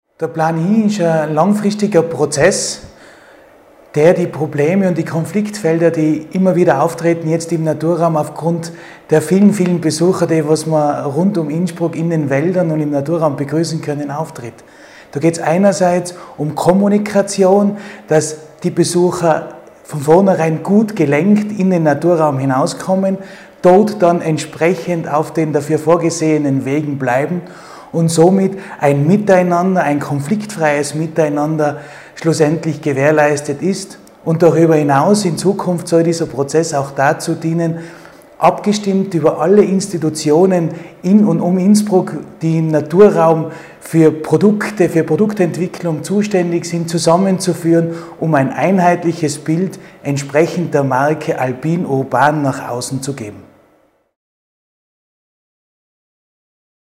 O-Ton von Stadtrat Gerhard Fritz